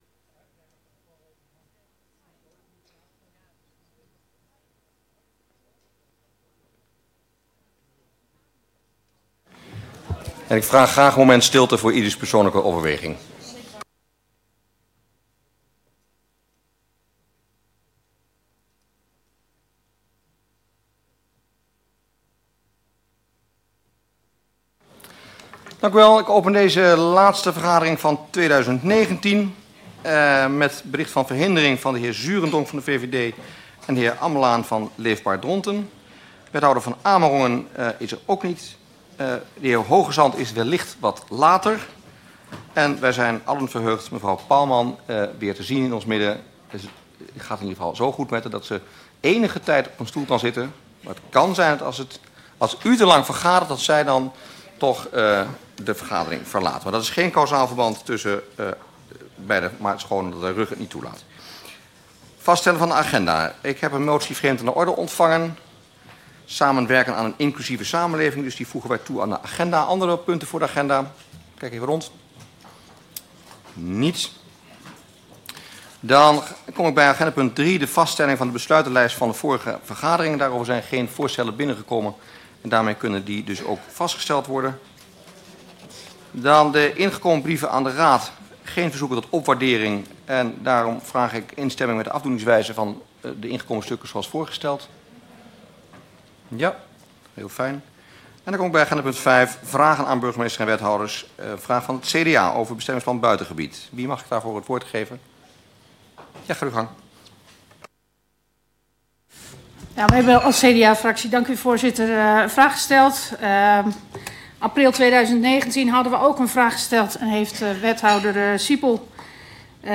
Raadsvergadering 19 december 2019 19:30:00, Gemeente Dronten
Locatie: Raadzaal